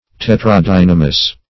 Search Result for " tetradynamous" : The Collaborative International Dictionary of English v.0.48: Tetradynamian \Tet`ra*dy*na"mi*an\, Tetradynamous \Tet`ra*dyn"a*mous\, a. (Bot.)